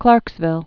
(klärksvĭl)